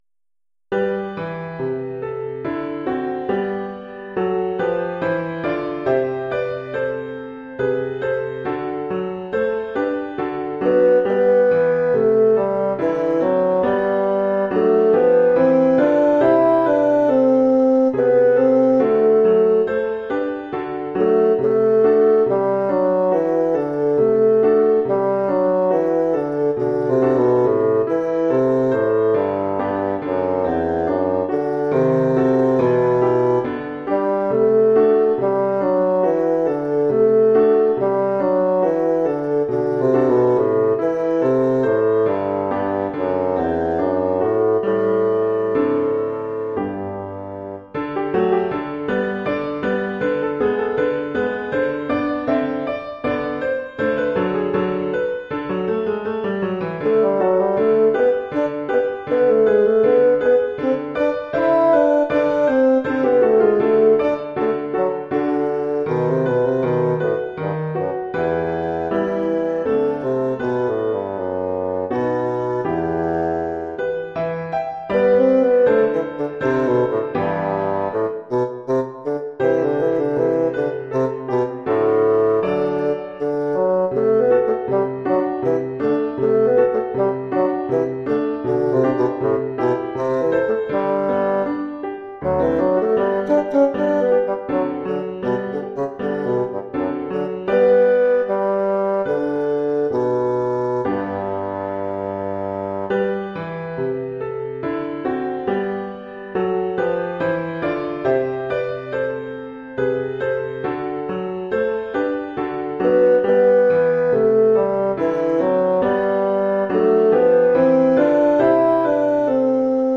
Oeuvre pour basson et piano.